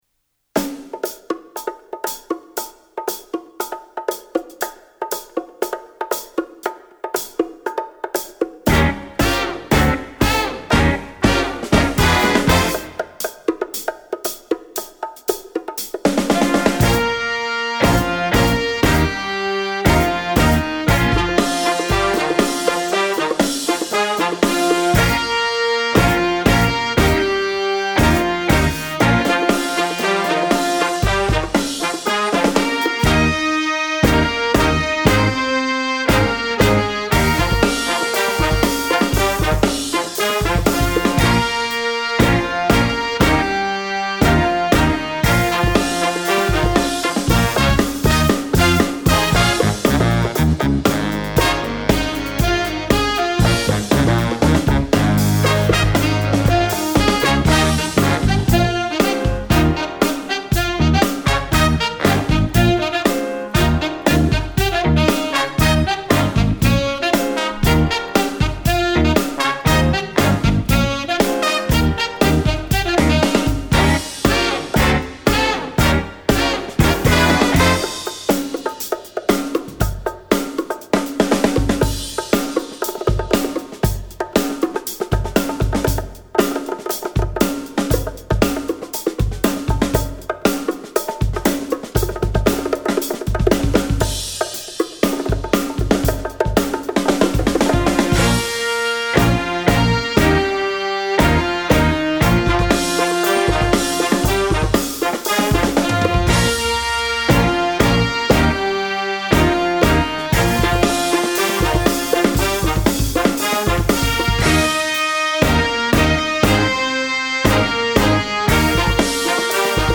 Répertoire pour Jazz band - Jazz Ensemble